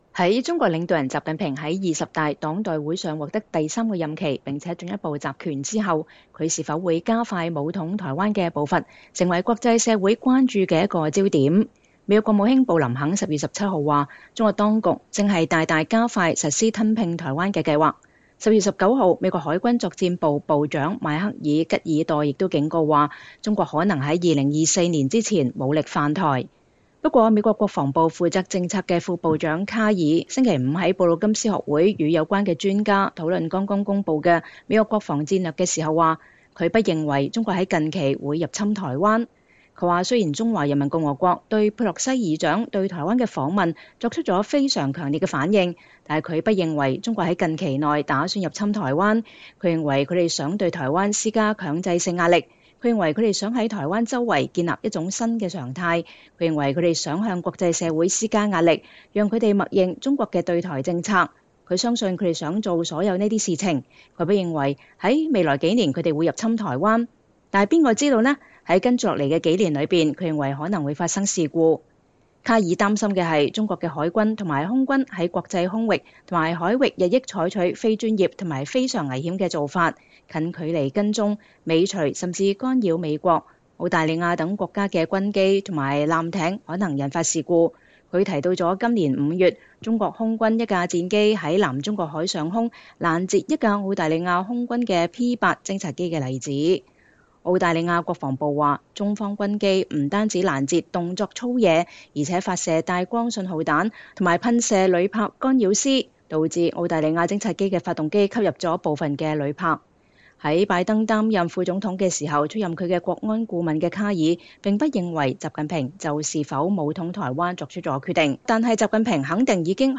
2022年11月4日，美國國防部負責政策的副部長卡爾在布魯金斯學會的一場對話上。
不過美國國防部負責政策的副部長卡爾(Colin Kahl)星期五在布魯金斯學會與有關專家討論剛剛公佈的美國國防戰略時表示，他不認為中國在近期會入侵台灣。